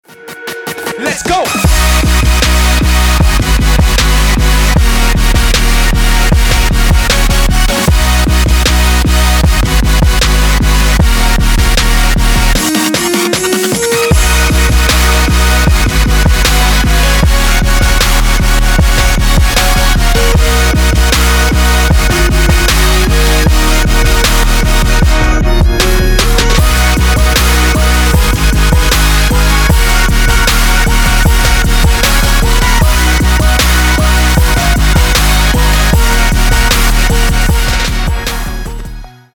club
electro